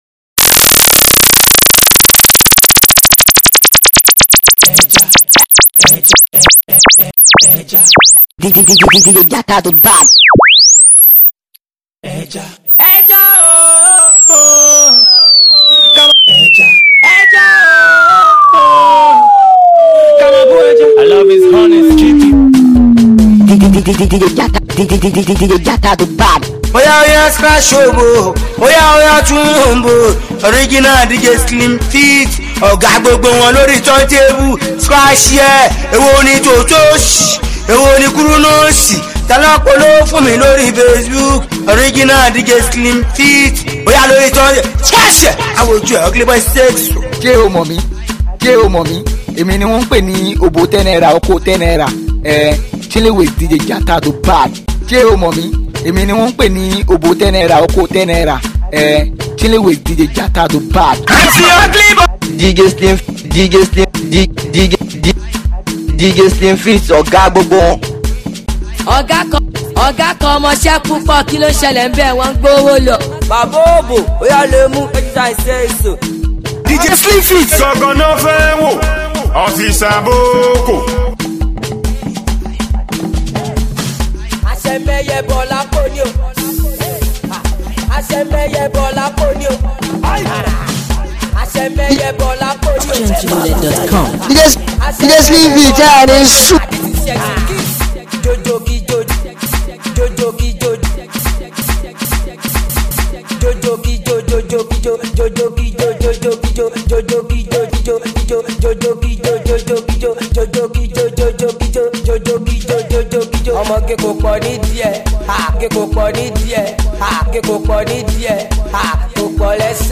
Street mix